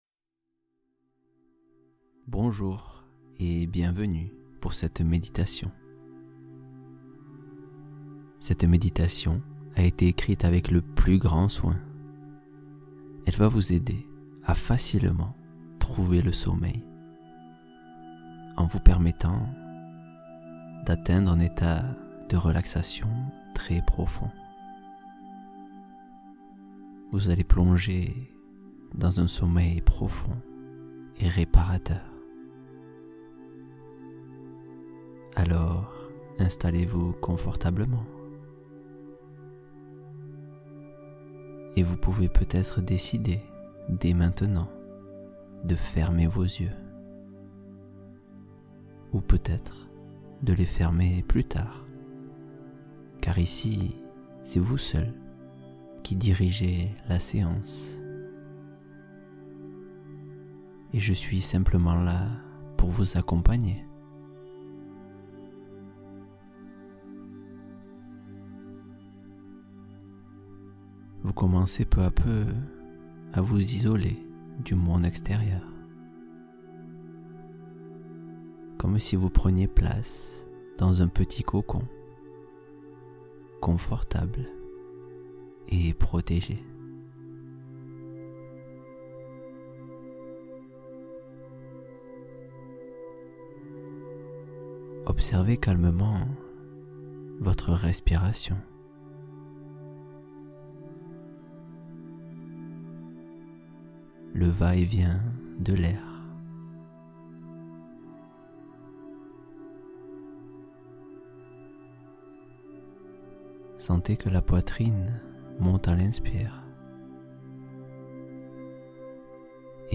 Sommeil du soir — Détente guidée pour conclure la journée